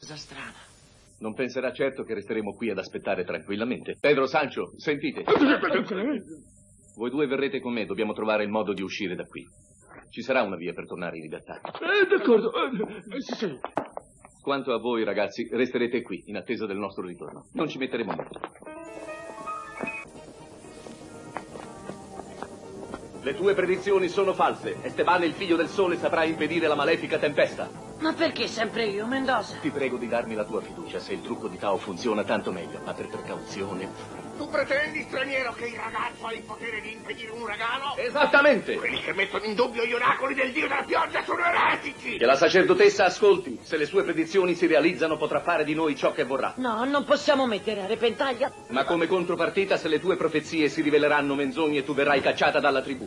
nel cartone animato "Esteban e le misteriose cittą d'oro", in cui doppia Mendoza.